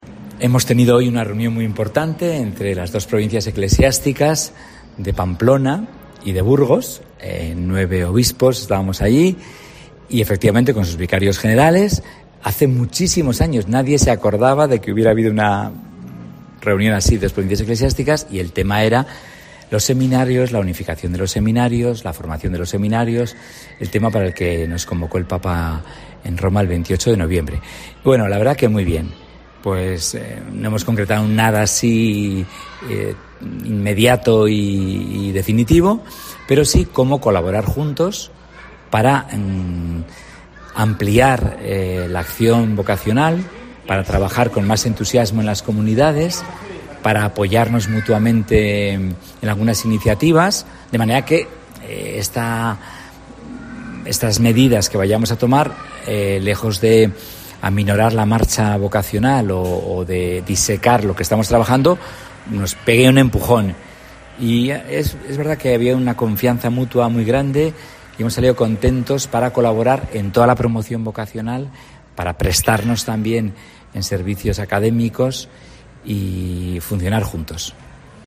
D. Juan Carlos Elizalde, Obispo de Vitoria-Gasteiz
Puedes escuchar la vaoración que ha hecho Monseñor Juan Carlos Elizalde para COPE clickando en la imagen superior.